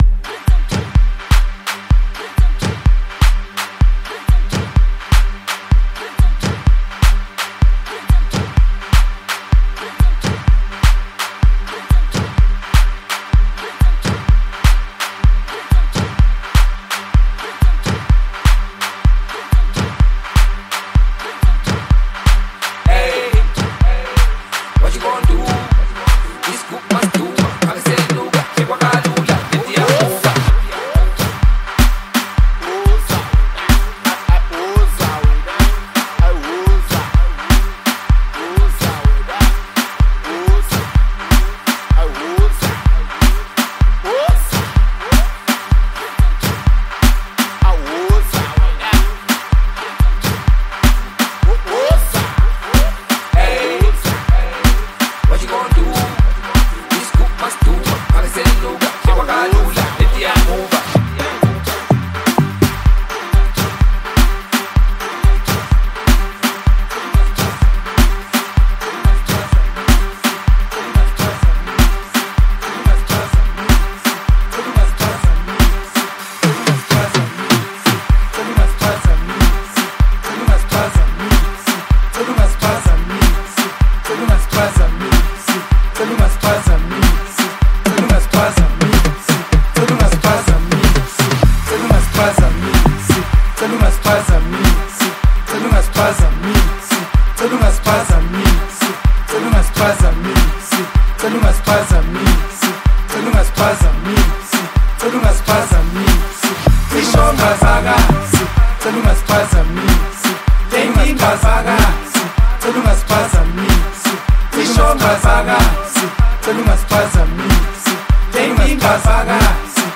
is a vibrant music tune